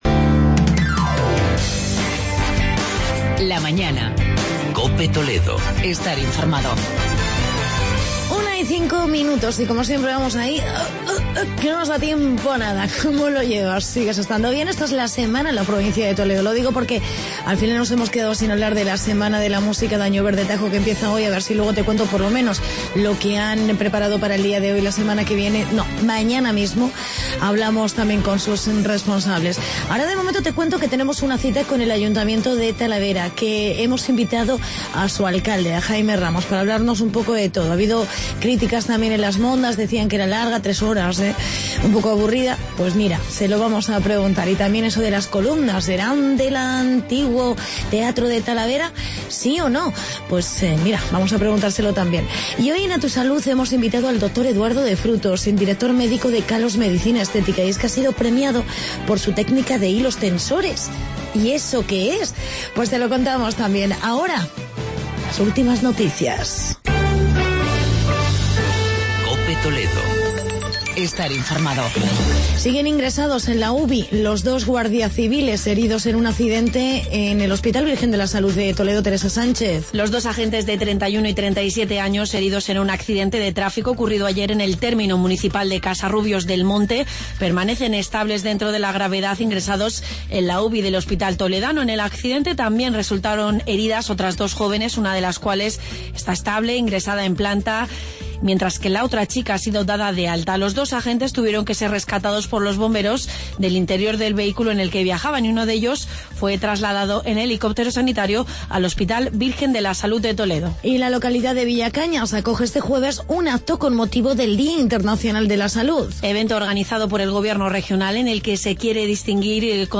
Entrevista con Jaime Ramos, alcalde de Talavera